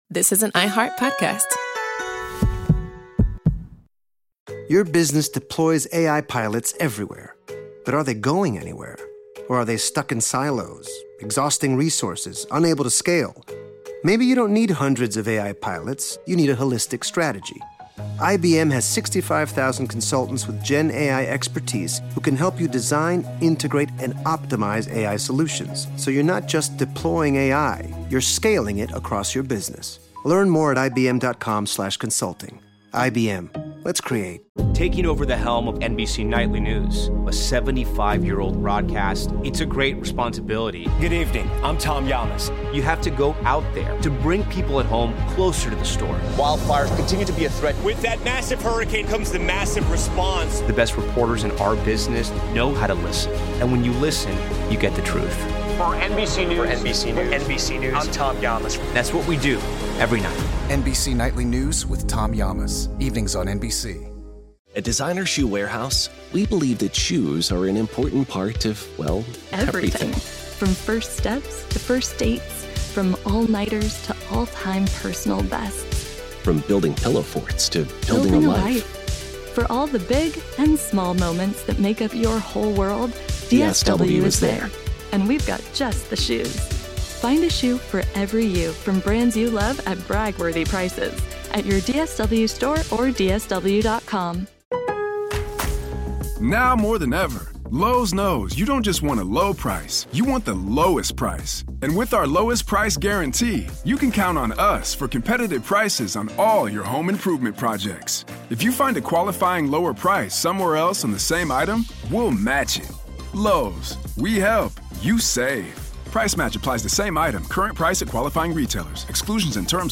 Here's the story of his early broadcasting career, told in his own words.